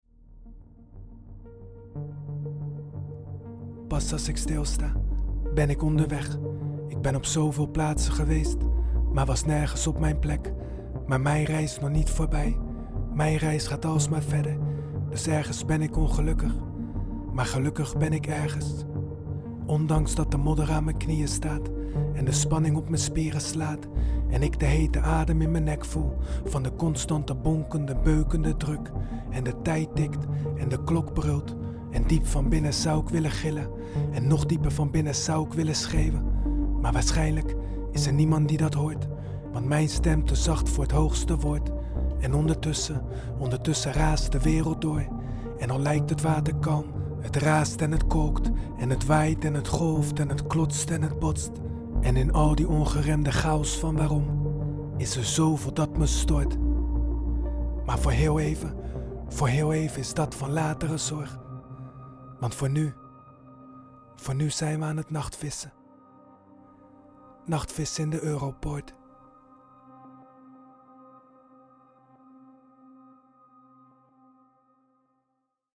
Zijn stem zacht en rauw.
Klanken kalmerend en scherp.